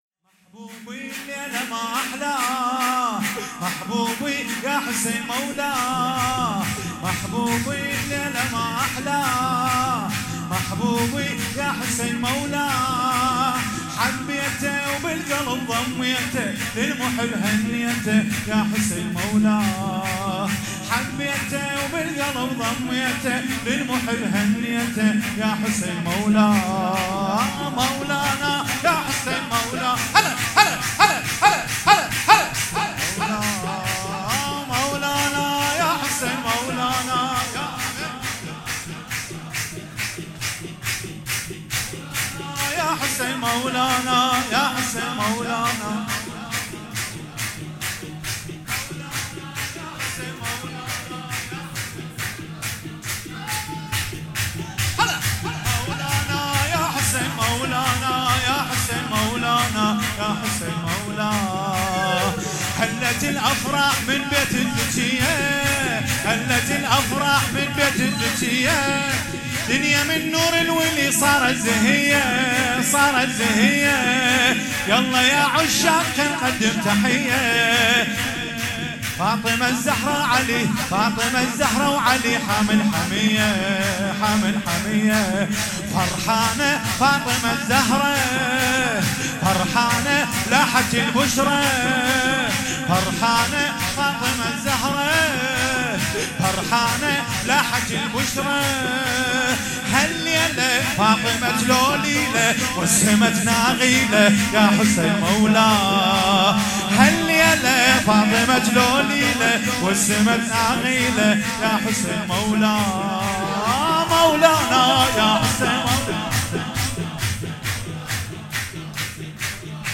ولادت انوار کربلا
شور